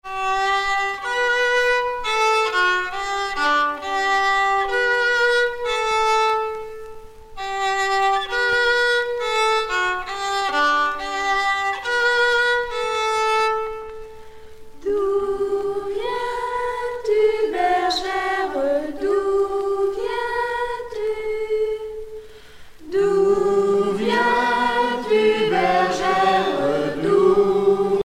Genre dialogue
Catégorie Pièce musicale éditée